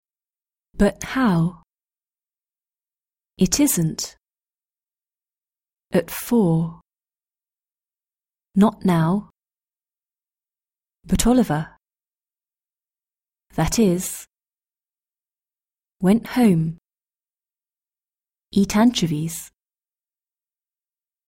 Glottal Stop:  ‘but-how’, ‘at-four’, ‘not-now’, ‘went-home’
british-english-british-accent-glottal-stop-5.mp3